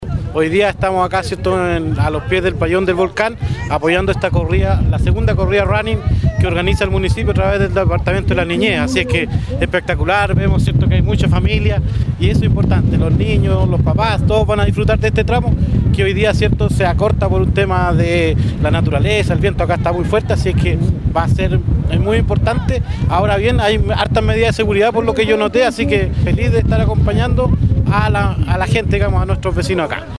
En la zona de salida y meta, el concejal y presidente de la Comisión de Deportes del Concejo Municipal, Claudio Cortez, comentó que el evento “se acorta por un tema de la naturaleza, el viento acá está muy fuerte, pero hay hartas medidas de seguridad por lo que yo noté, así es que feliz de estar acompañando a la gente y a nuestros vecinos”.
Claudio-Cortez-concejal-y-Pdte-Comision-de-Deportes-del-Concejo.mp3